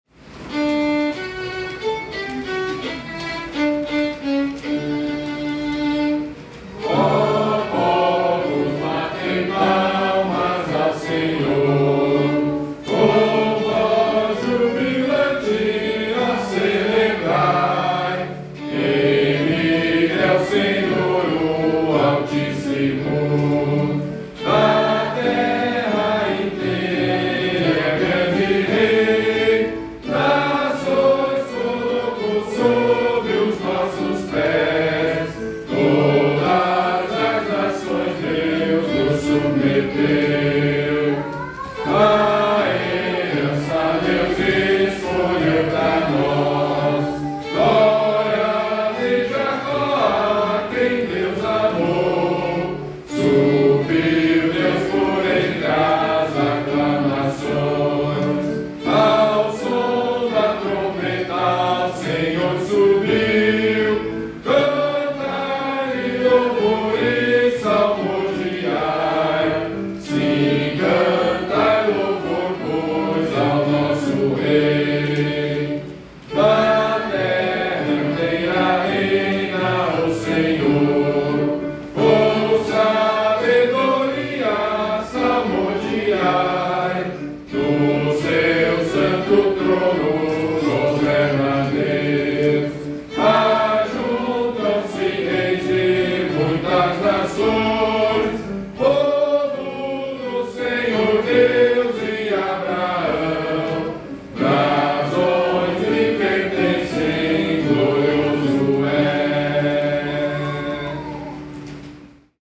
Modo: jônio
Harmonização: Claude Goudimel, 1564
salmo_47A_cantado.mp3